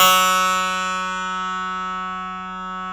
53s-pno07-F1.aif